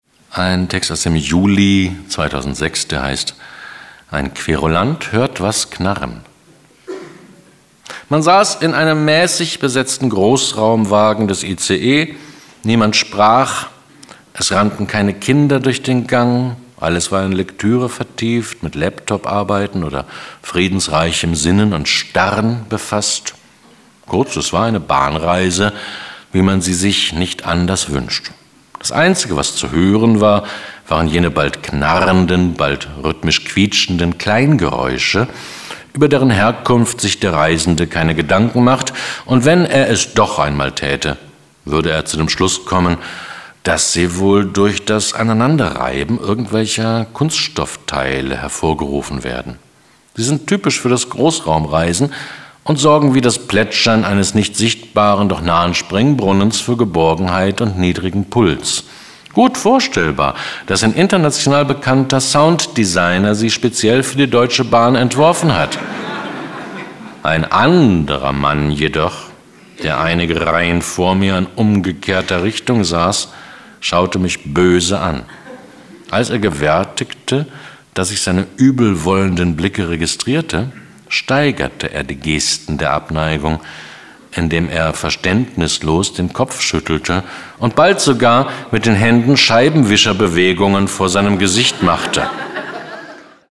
Max Goldt (Sprecher)